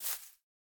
Minecraft Version Minecraft Version 25w18a Latest Release | Latest Snapshot 25w18a / assets / minecraft / sounds / block / azalea / step4.ogg Compare With Compare With Latest Release | Latest Snapshot
step4.ogg